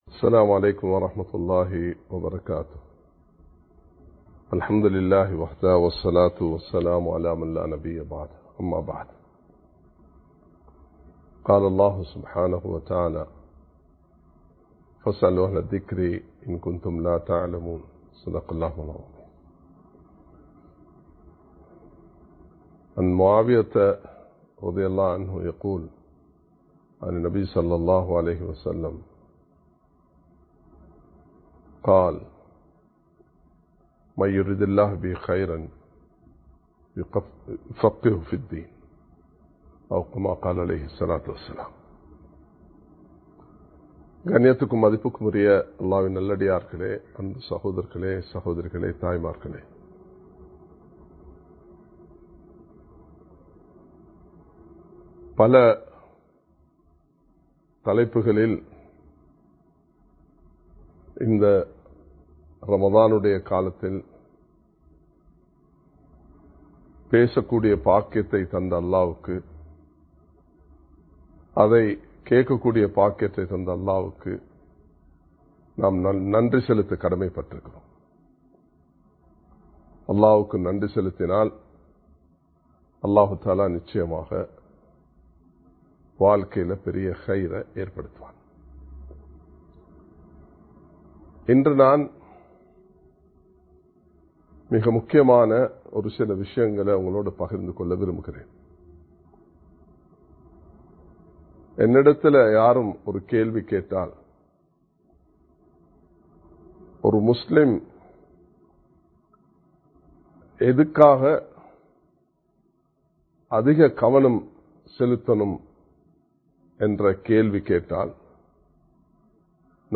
ஈமானும் இல்மும் (Believeness and Knowledge) | Audio Bayans | All Ceylon Muslim Youth Community | Addalaichenai
Live Stream